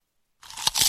Sound Effects
Pvz Zombie Eating